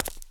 stone1.ogg